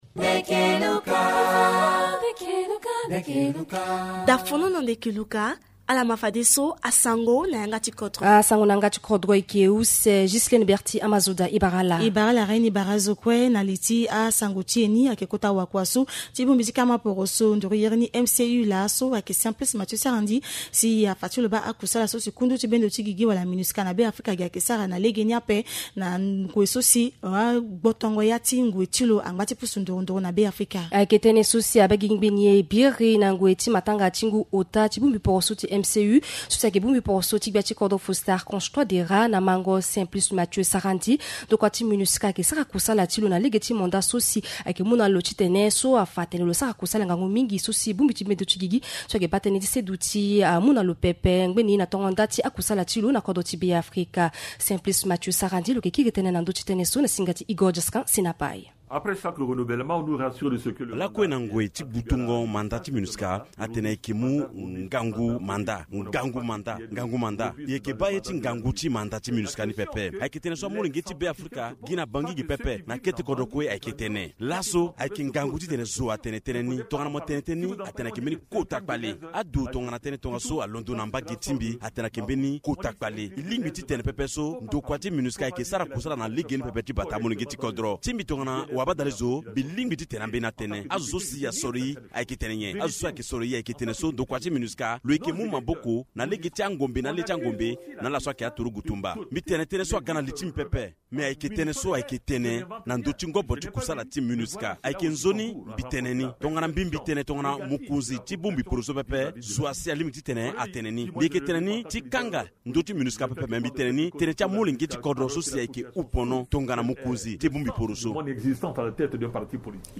Journal en Sango